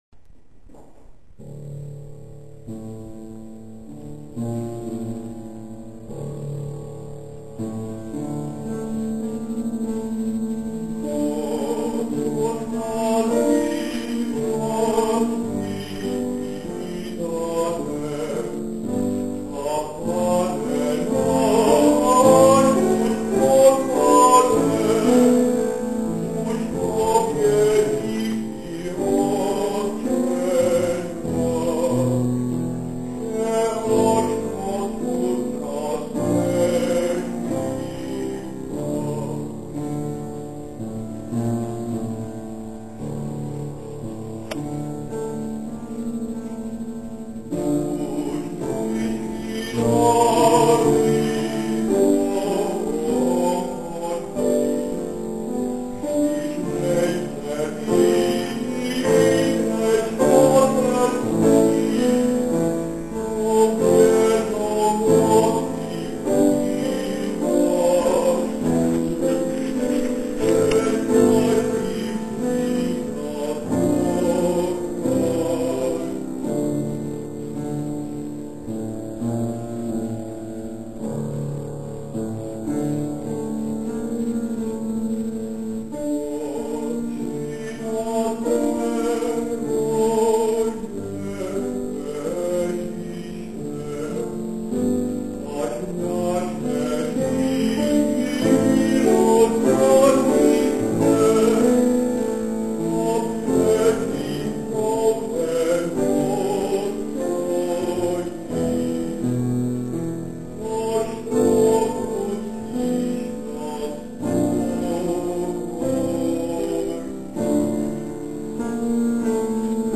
27. komorní koncert na radnici v Modřicích
Ukázkové amatérské nahrávky WMA:
Z chrámových písní, zpěv
klavír